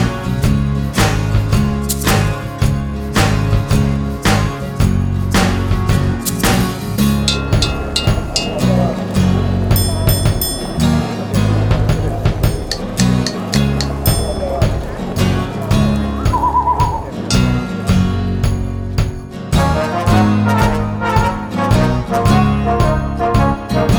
no Backing Vocals with Voiceover Pop (1960s) 2:47 Buy £1.50